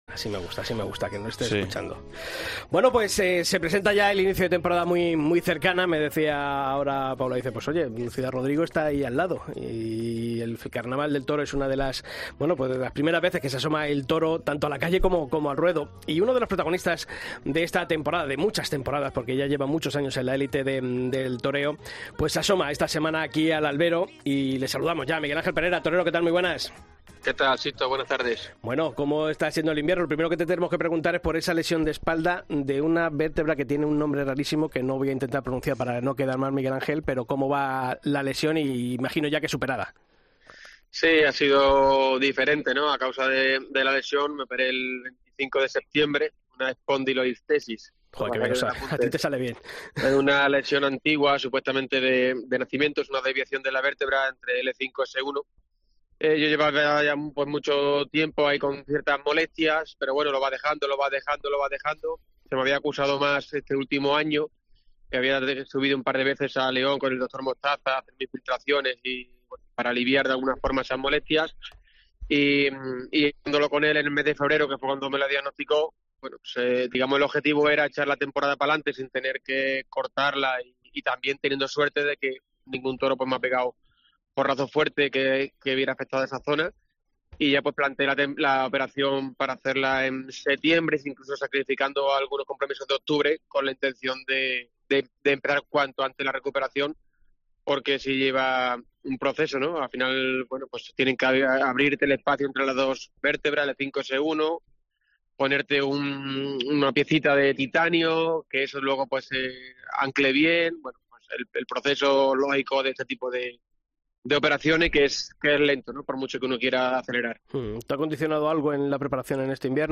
En una extensa entrevista en el programa ‘El Albero’ de la cadena COPE, el diestro extremeño ha repasado su estado físico y ha reivindicado con contundencia el papel de las figuras consolidadas en el toreo actual, mostrando su malestar por ciertas corrientes de opinión que, a su juicio, las denostan de manera injusta.